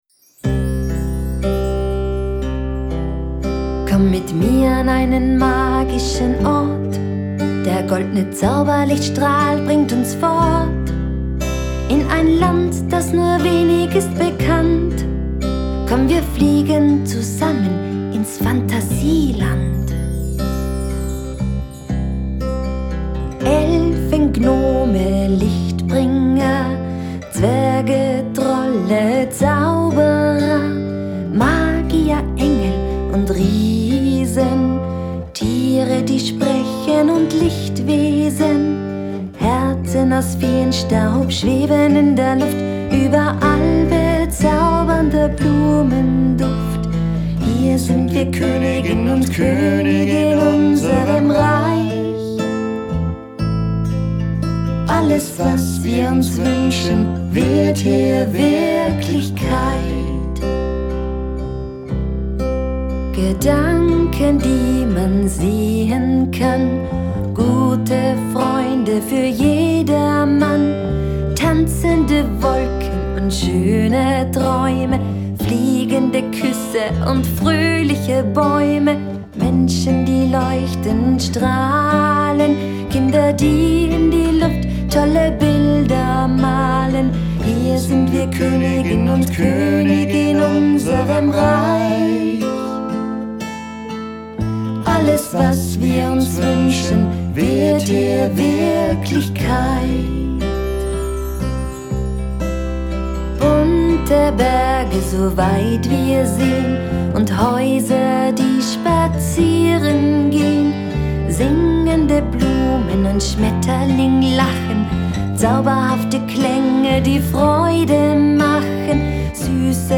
“PHANTASIELAND”. Genre: Blues.